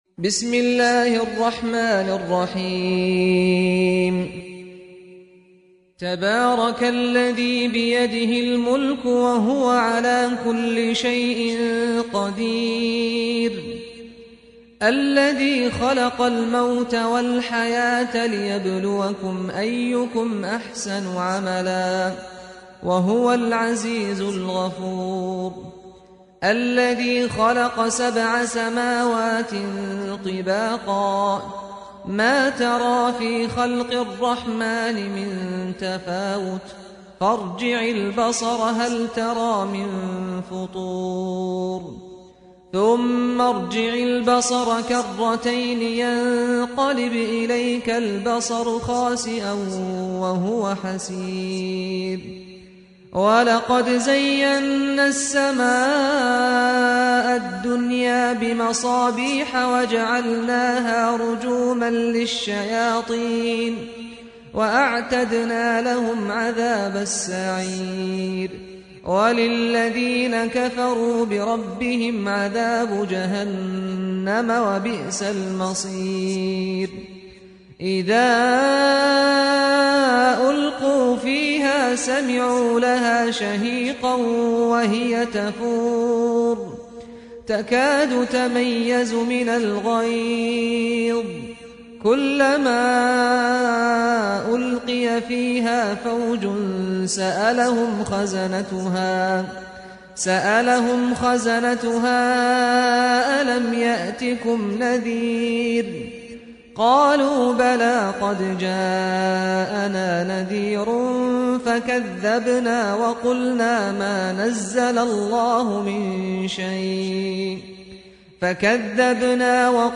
Islam-media, Saad Al-Ghamidi : récitateur du coran
Le saint Coran par Saad Al-Ghamidi